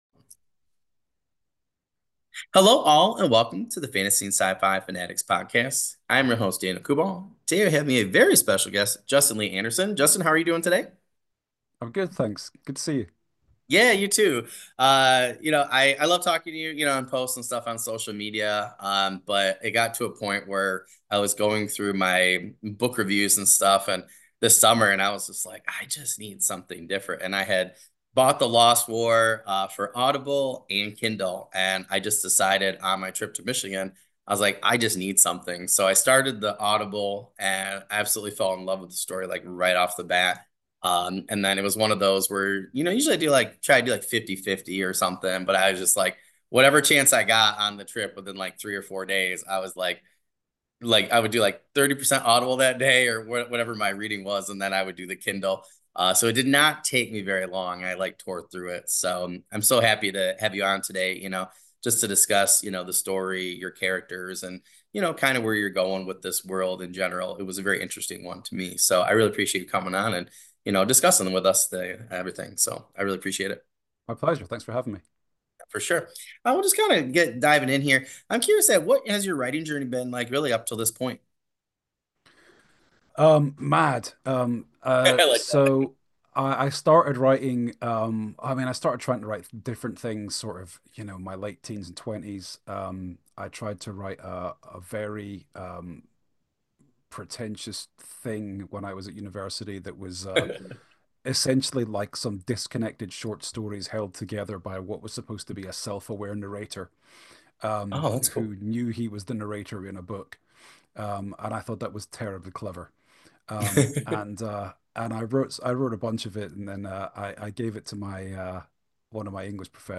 For this episode of Season 4, I had the pleasure of interviewing fantasy, horror, and scifi author